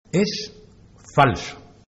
Los sonidos del maquinillo